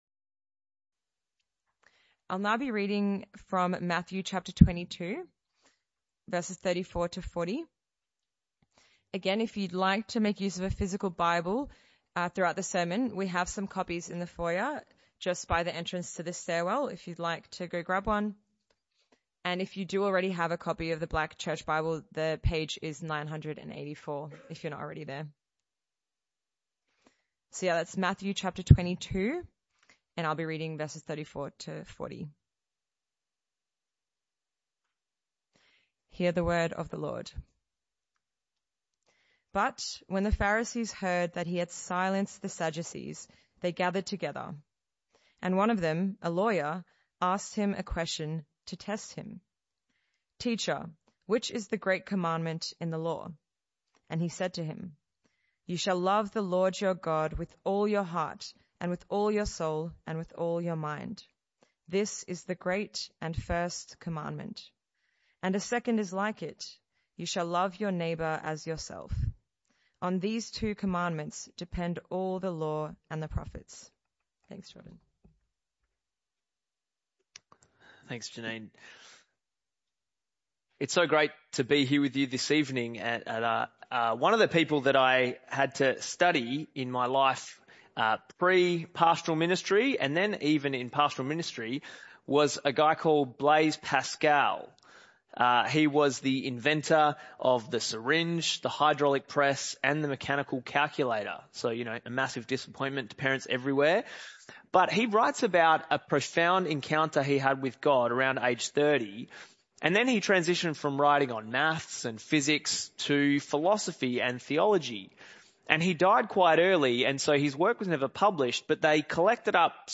This talk was a one-off talk in the PM Service.